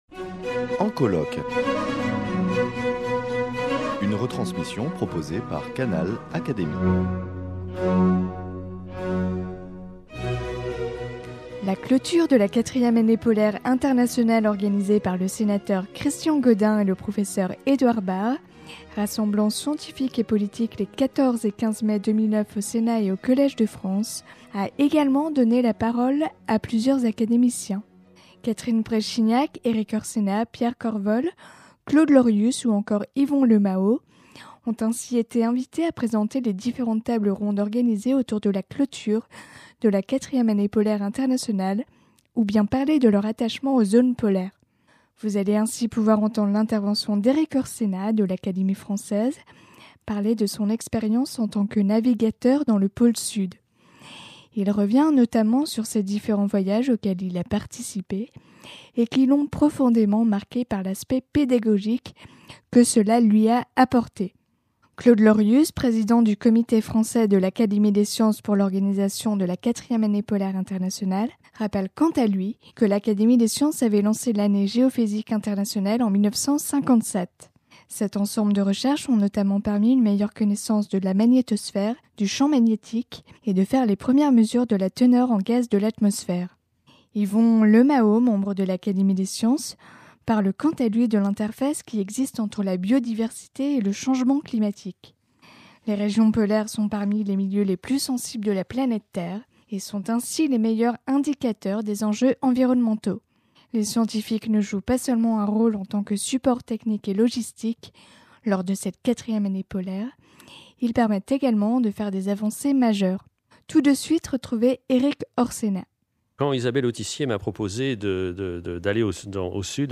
Canal Académie vous propose d’écouter certains de leurs discours. L’Homme doit prendre conscience de son impact sur le changement climatique s’il veut encore avoir la chance de profiter de ces grands espaces que sont les pôles.